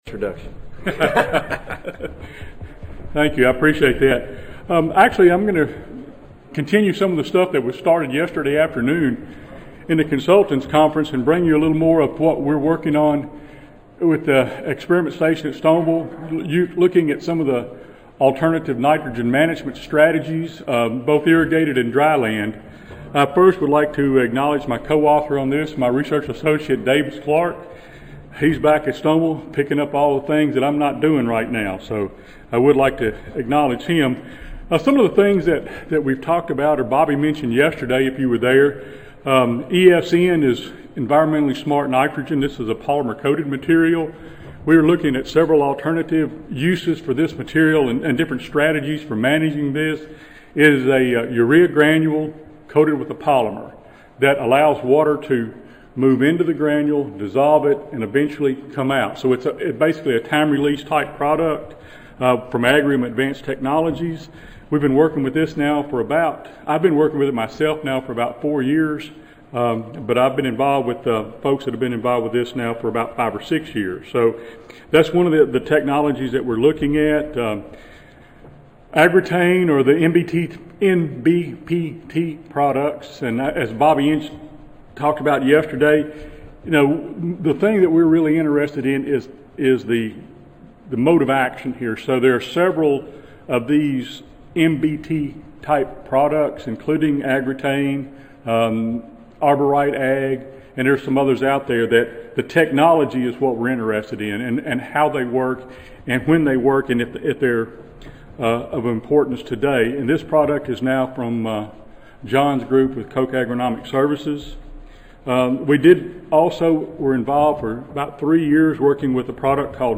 Mississippi State University Audio File Recorded Presentation